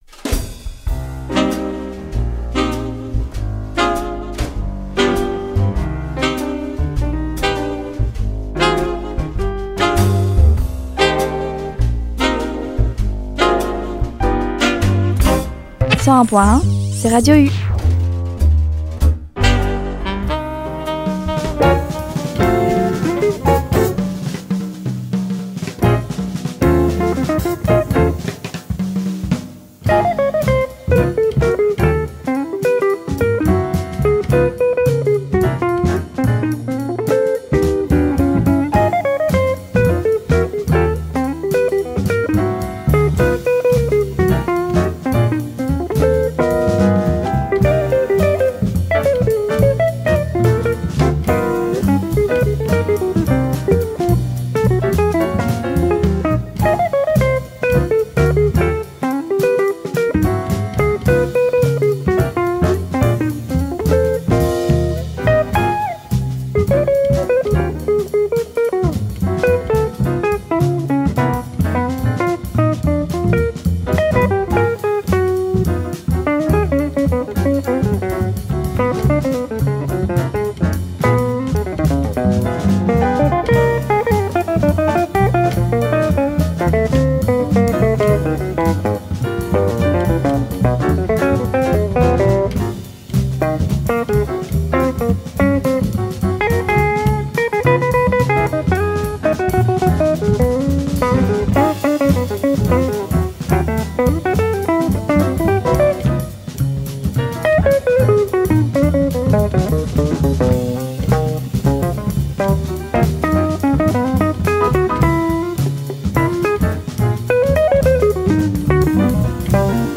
Retrouvez ma sélection JAZZ